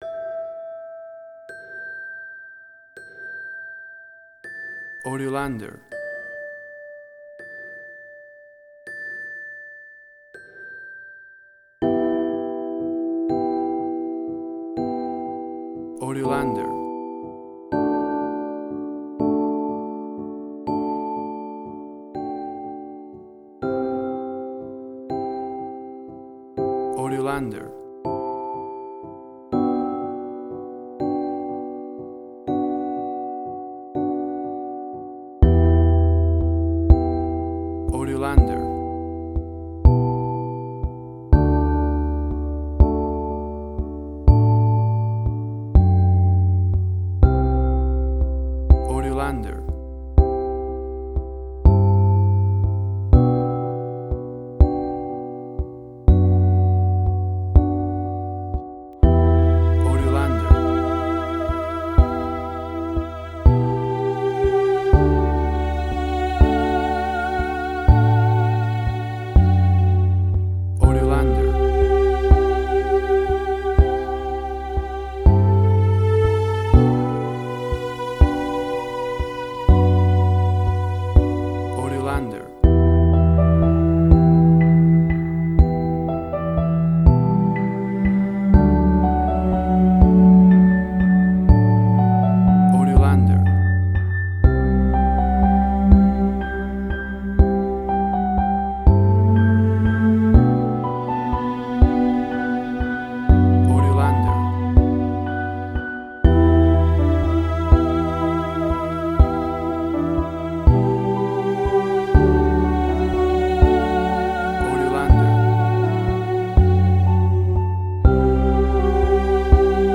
Suspense, Drama, Quirky, Emotional.
WAV Sample Rate: 16-Bit stereo, 44.1 kHz
Tempo (BPM): 122